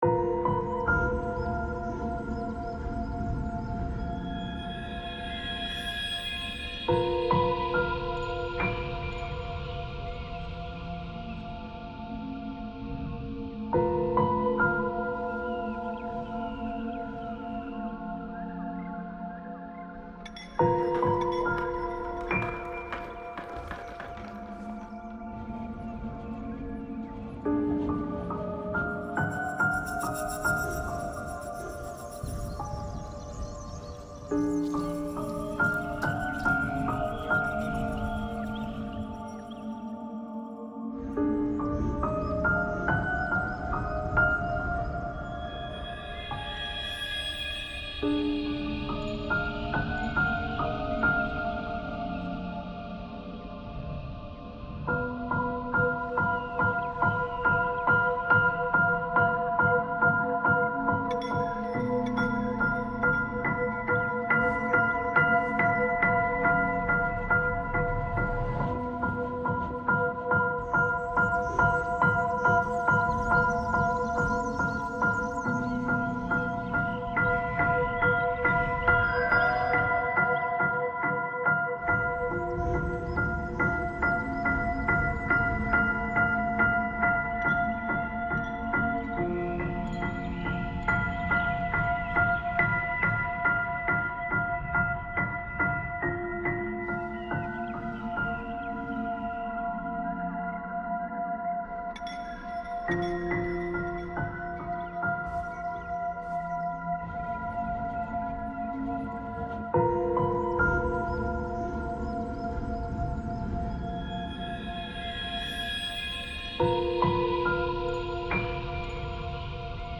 暗くて不気味な場所、孤独感、寂しさ、闇の中を想像しながら作ったホラー系のBGMです。